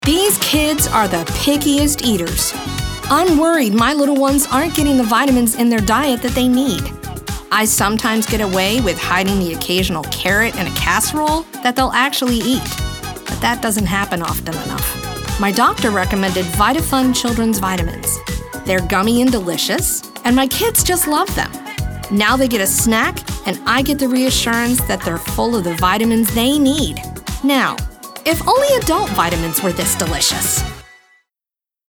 announcer, confident, cool, genuine, middle-age, perky, retail, upbeat